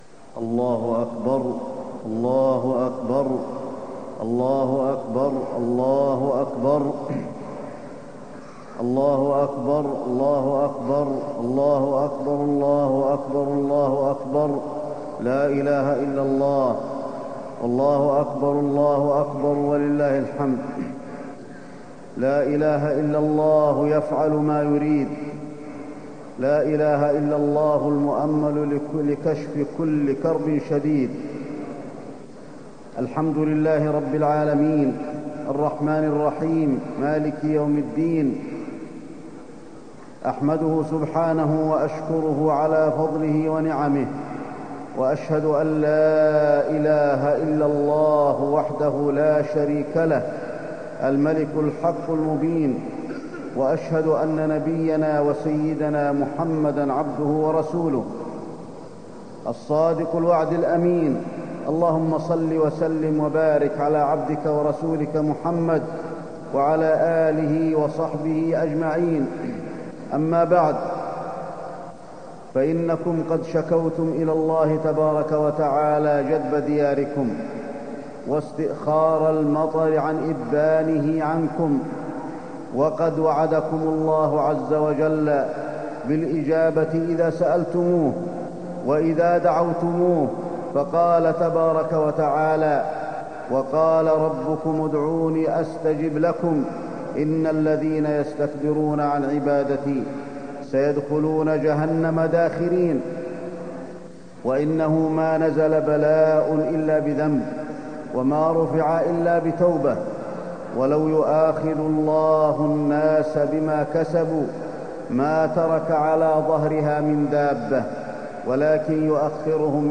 خطبة الاستسقاء - المدينة- الشيخ علي الحذيفي